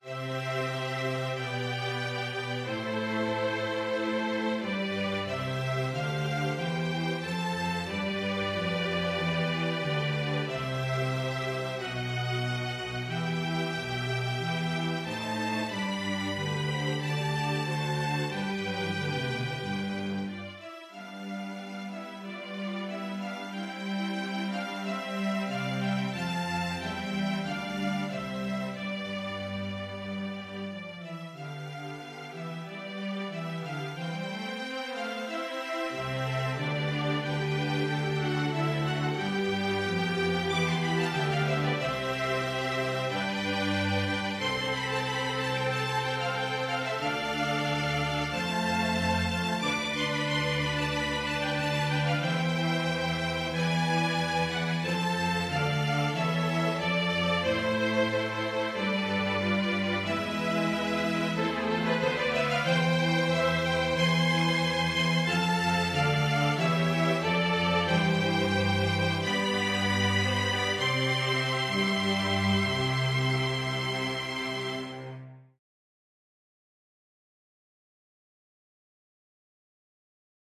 String Orchestra